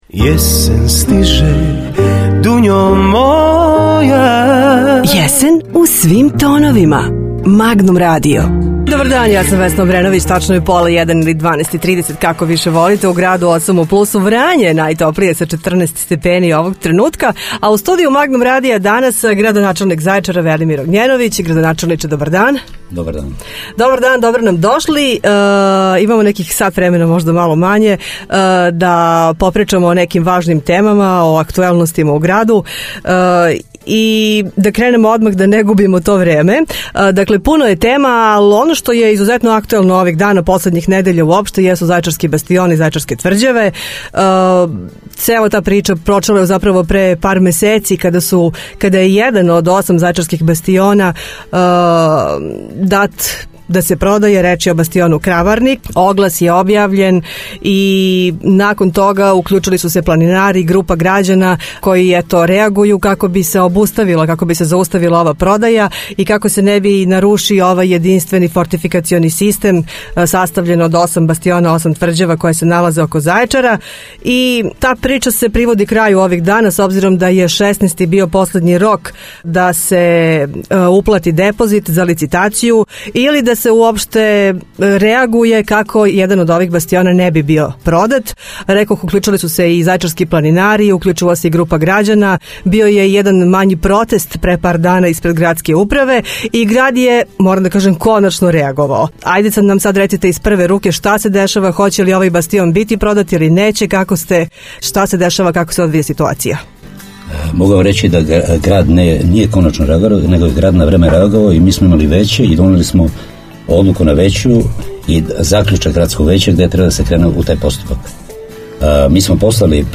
IZ PRVE RUKE: Gradonačelnik Zaječara gost Magnum radija (AUDIO) : Radio Magnum 103 MHz